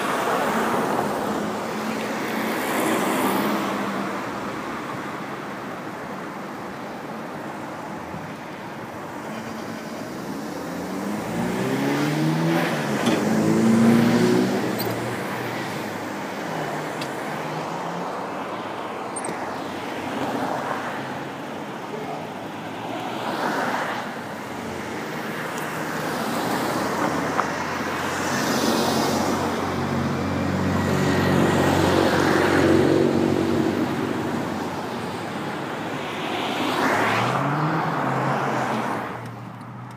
hempsteadturnpike1 2/14 7pm Red Light Hempstead Turnpike Cars passing, wind, unknown clanking sound, accelerating
2/14 7pm Red Light Hempstead Turnpike
hempsteadturnpike11.mp3